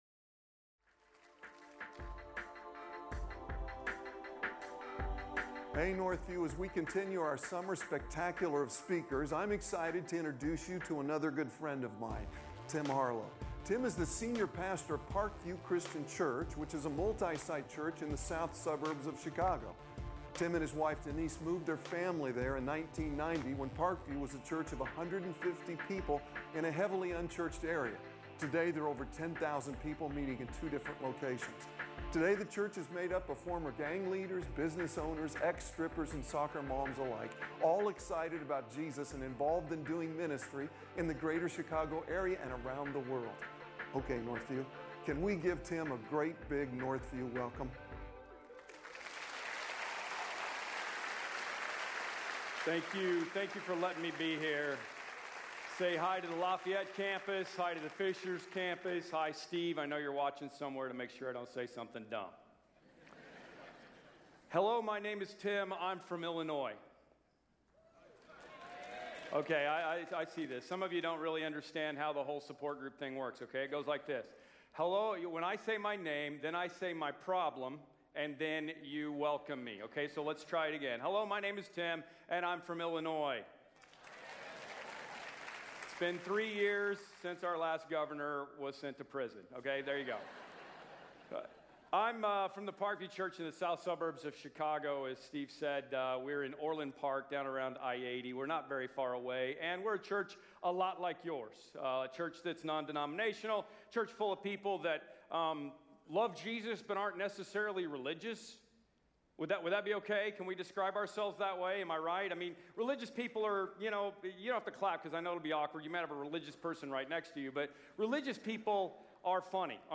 guest pastor